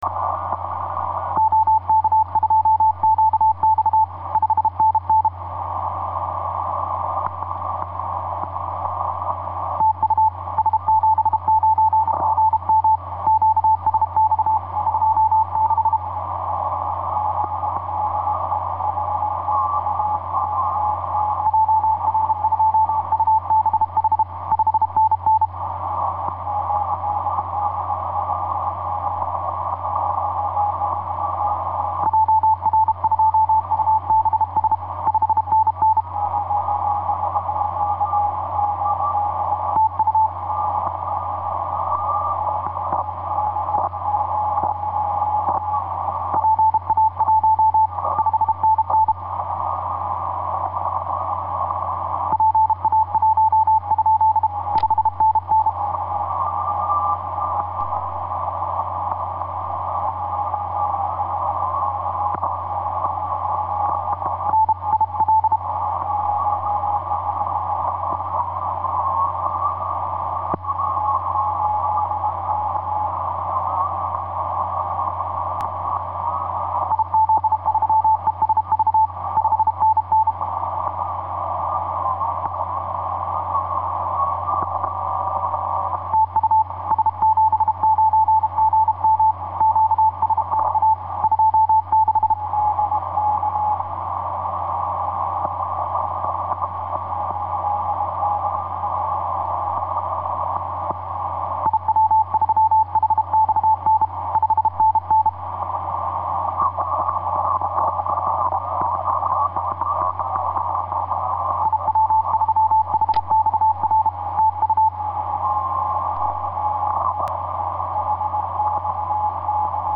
cw